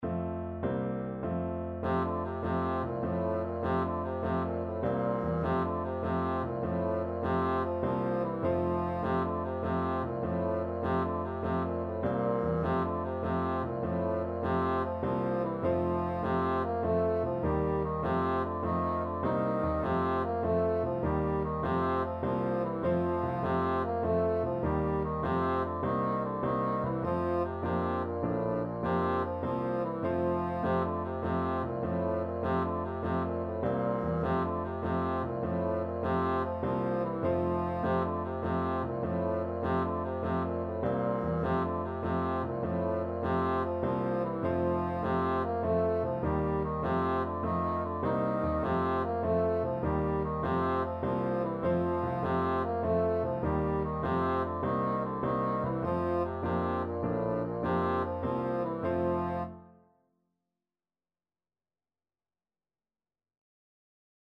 BassoonBassoon
Traditional Music of unknown author.
9/8 (View more 9/8 Music)
A3-Bb4
F major (Sounding Pitch) (View more F major Music for Bassoon )
Instrument: